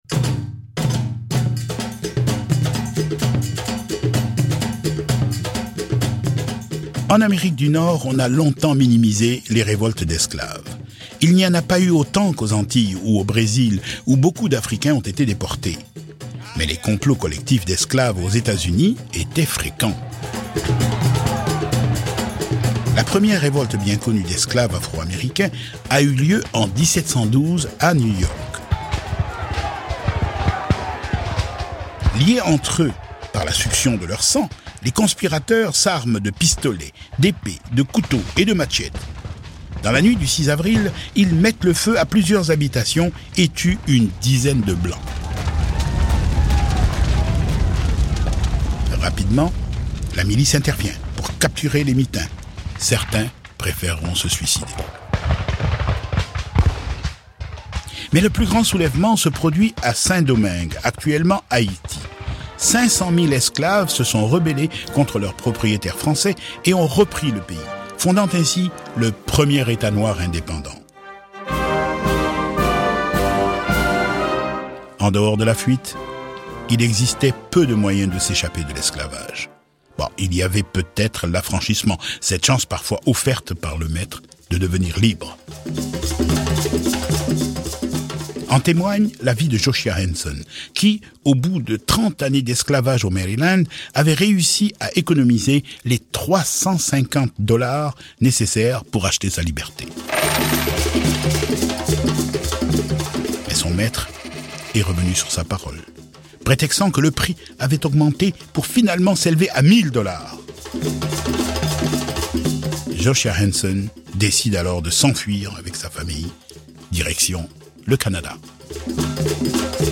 Sunu & Diansa (Mamady Keïta) Trad. Mali – Fonti Musicali FM D195
La Dessalinienne (The Regimental Band of the Coldstream Guards / Nicolas Geffard)